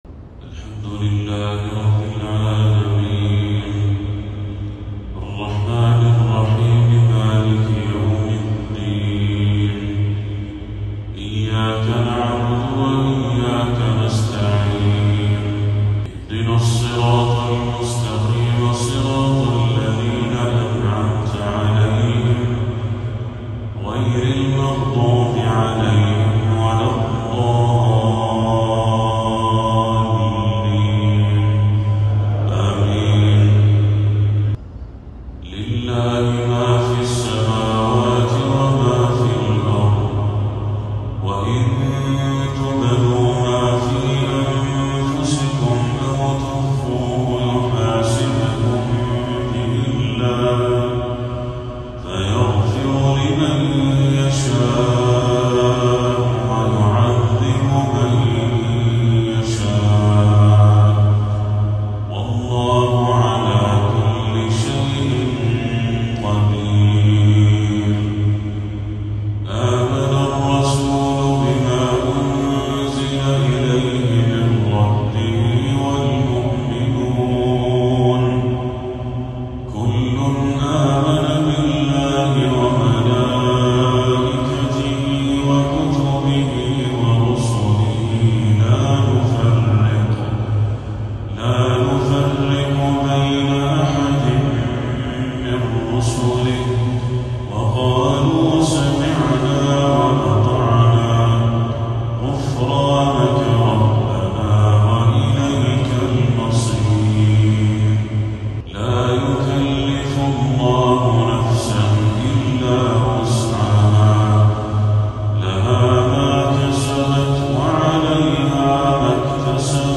| تلاوة بهية لخواتيم سورتي البقرة وهود للشيخ بدر التركي | عشاء 6 ربيع الأول 1446هـ > 1446هـ > تلاوات الشيخ بدر التركي > المزيد - تلاوات الحرمين